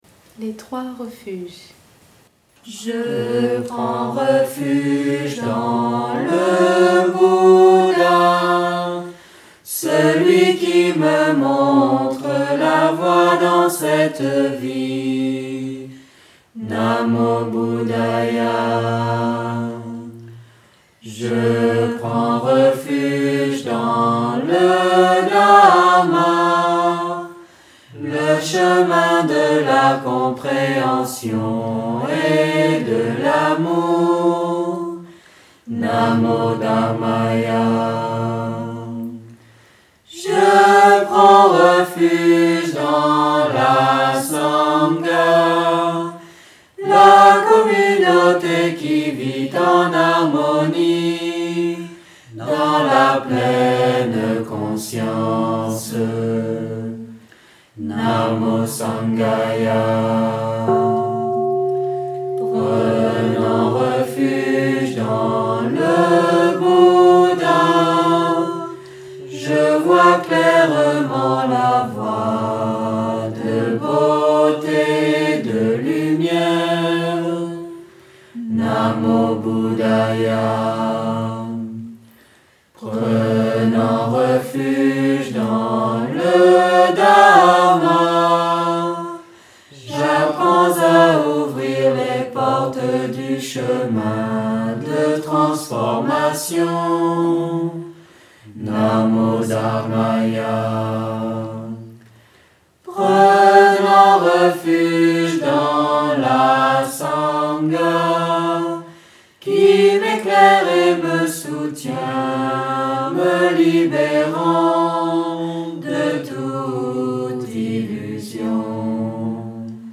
Chanson- Les Trois Refuges.mp3